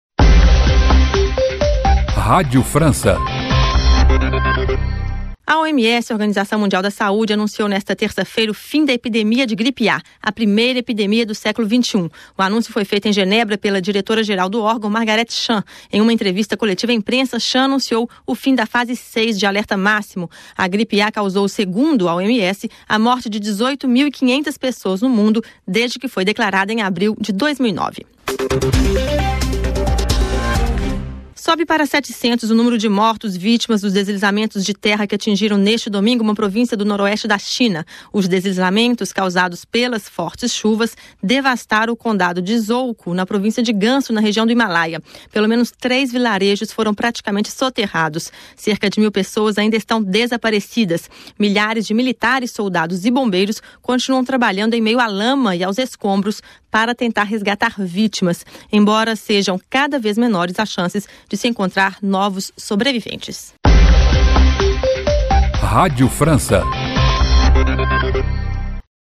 Boletim da Rádio França Internacional.